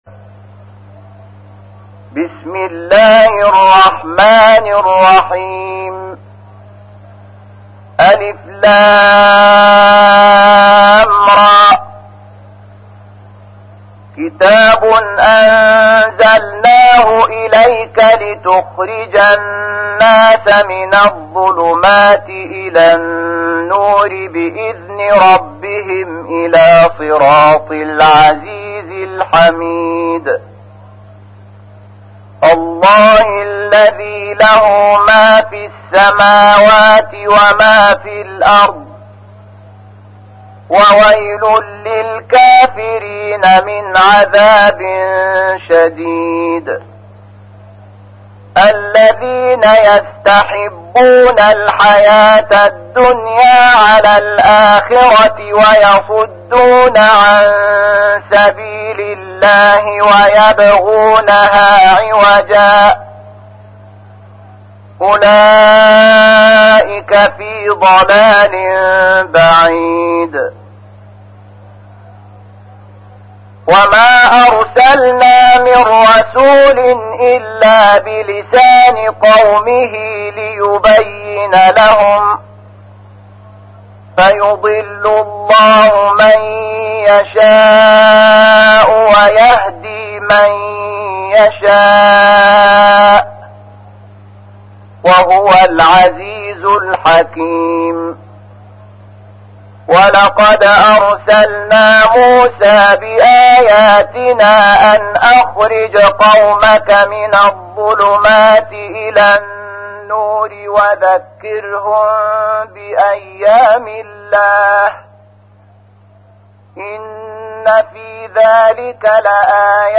تحميل : 14. سورة إبراهيم / القارئ شحات محمد انور / القرآن الكريم / موقع يا حسين